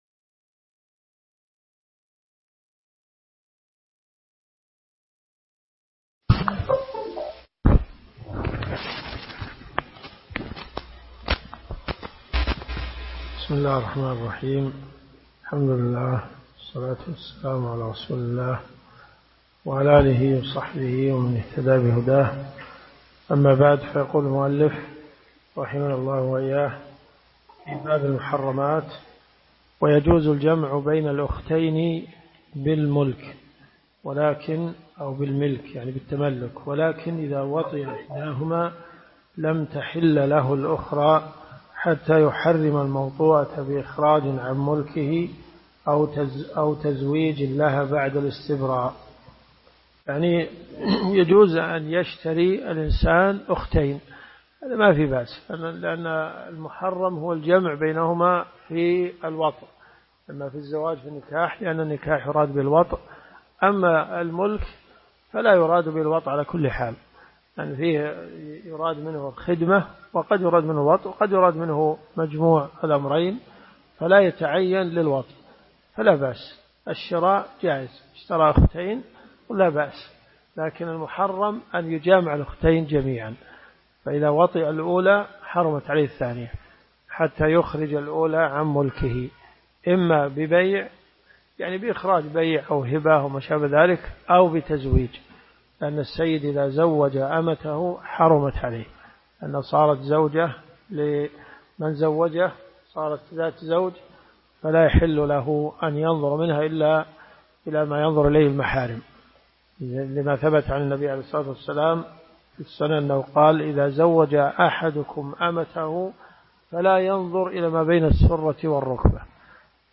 الصوت يتقطع في اماكن متفرقة